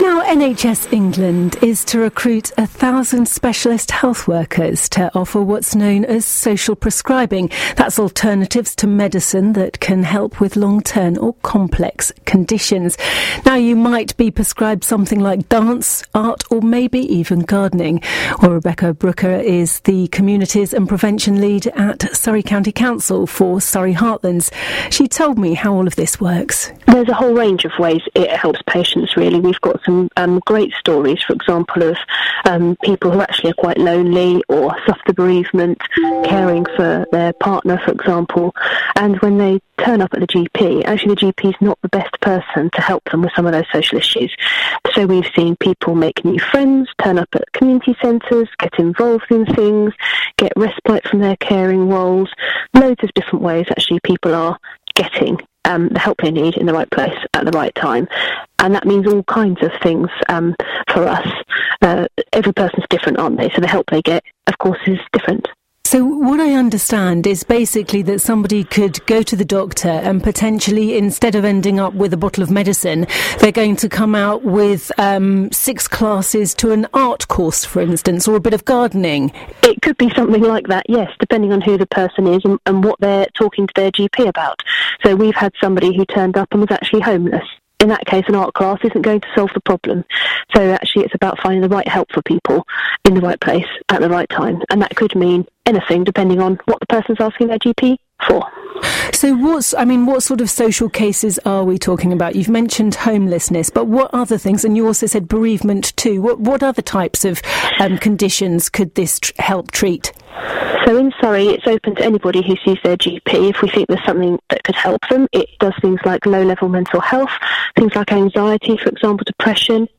AUDIO: BBC Surrey interview about social prescribing work in Surrey